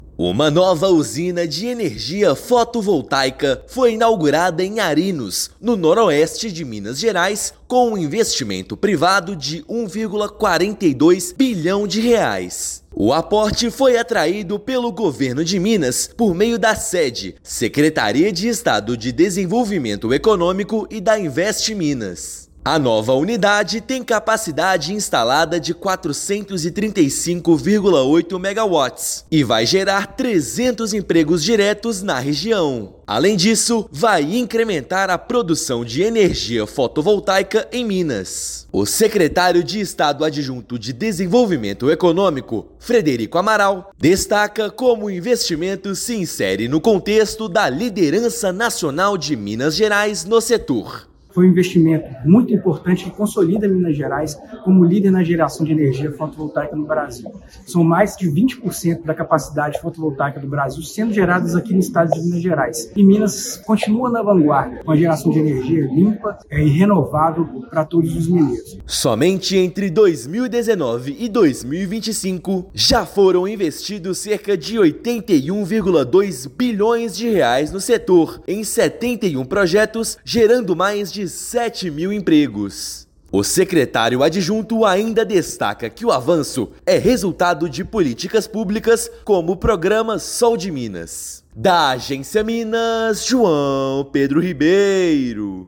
Projeto da Newave Energia S.A em Arinos vai gerar 300 empregos diretos. Ouça matéria de rádio.